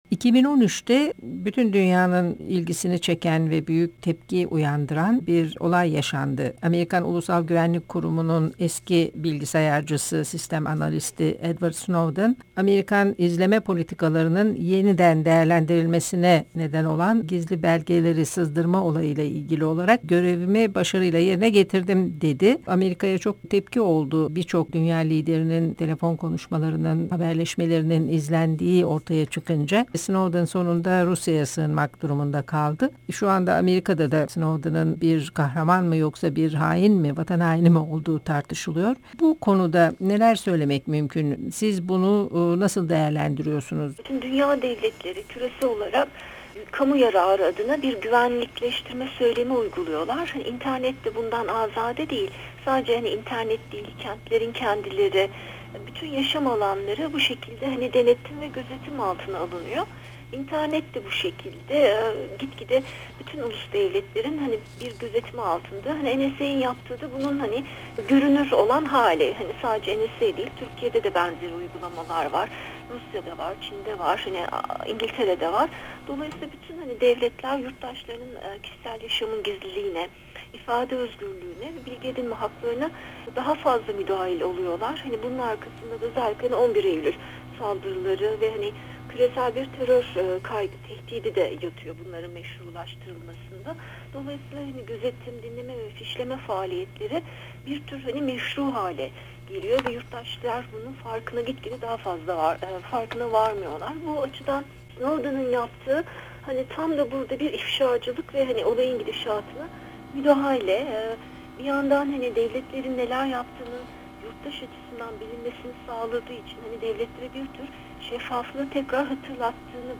Söyleşi